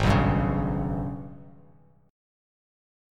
Listen to AbmM11 strummed